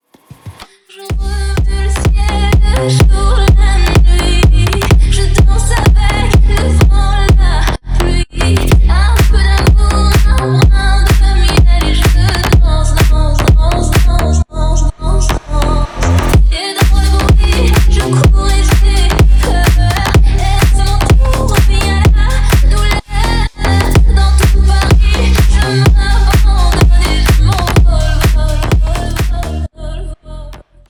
• Качество: 320, Stereo
женский вокал
Club House
клубные
slap house
Клубный ремикс